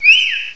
foongus.aif